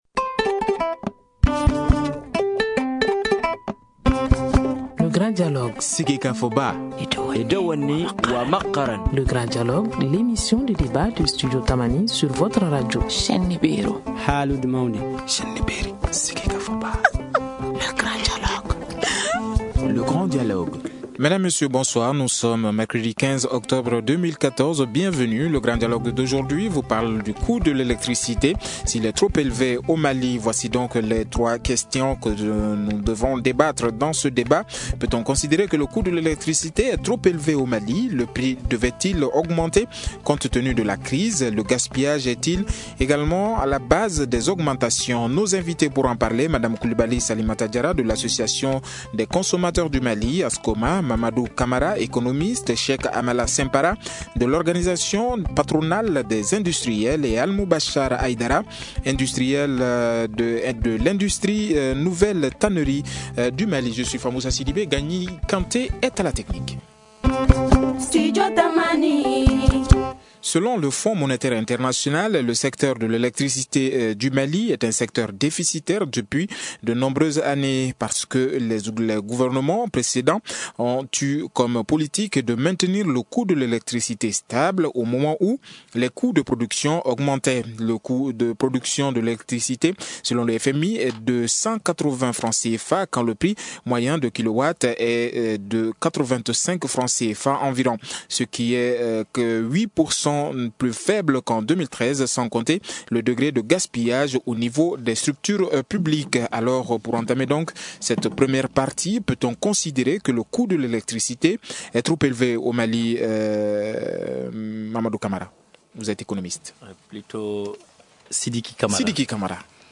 Voici les trois axes de ce débat : Peut-on considérer que le coût de l’électricité est trop élevé au Mali ? Le prix devait-il augmenter compte tenu de la crise ? Le gaspillage est-il à la base des augmentations ?